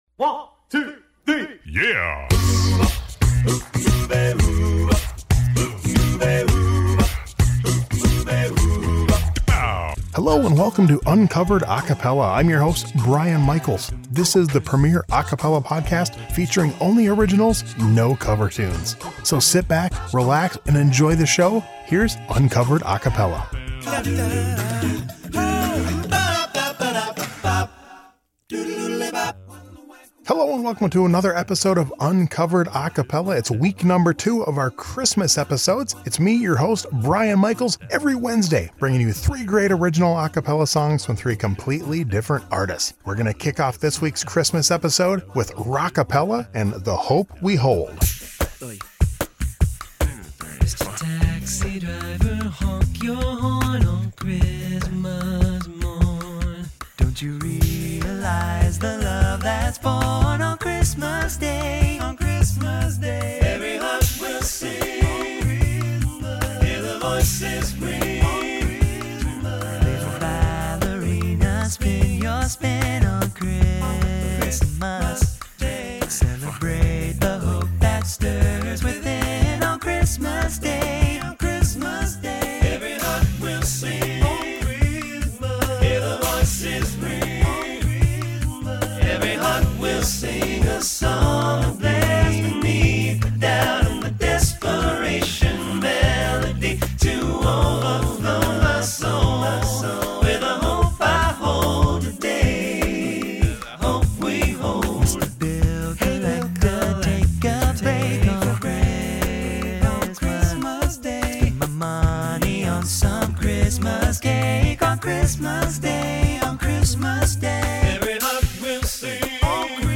3 original a cappella songs every week!